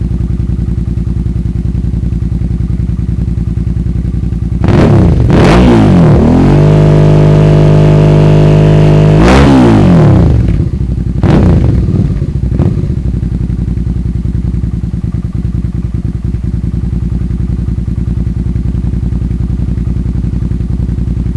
Außerdem gab es noch folgende Neuteile: Auspuffanlage BSM Future extreme, Five Stars Verkleidungsunterteil, Gericke Fußrasten, Lucas Stahlflexleitungen vorne und K&N Luftfilter kamen fast zeitgleich an mein Moped.
Klingt wirklich nett, und erst ohne den db-Killer, aber das mache ich nur auf der Rennstrecke ! (bis jetzt)
offen,
offen1_laufend.wav